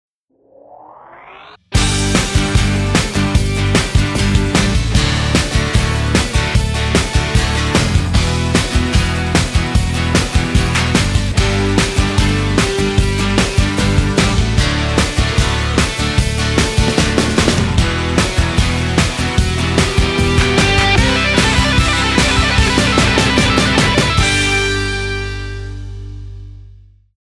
【HK18】欢快